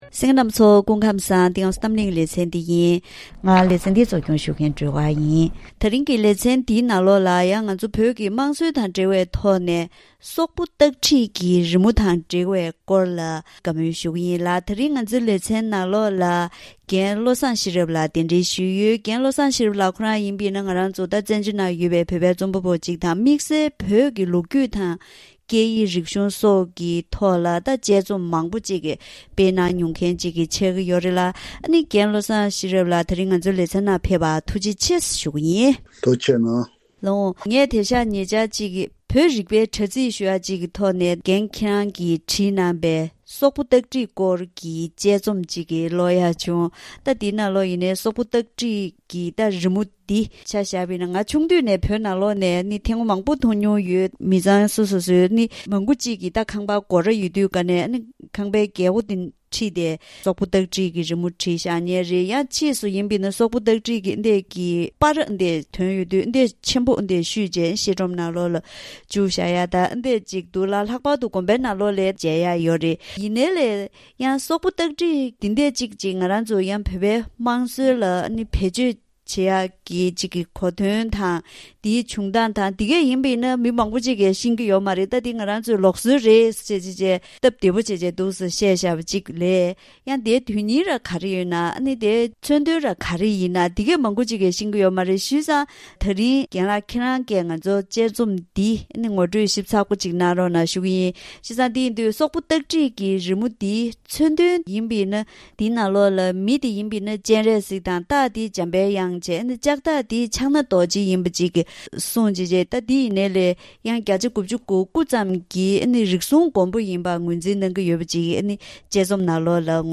ད་རིང་གི་གཏམ་གླེང་ལེ་ཚན་འདིའི་ནང་བོད་ཀྱི་དམངས་སྲོལ་དང་འབྲེལ་བའི་ཐོག་ནས་སོག་པོ་སྟག་ཁྲིད་ཀྱི་རི་མོ་འདི་དར་ཚུལ་དང་མཚོན་དོན། ངག་རྒྱུན་ལ་བཤད་སྲོལ་མི་འདྲ་བ་གང་ཡོད་པ། བོད་པས་རི་མོ་འདི་འབྲི་དགོས་དོན་ལ་སོགས་པའི་འབྲེལ་ཡོད་སྐོར་ལ་བཀའ་མོལ་ཞུ་གི་ཡིན།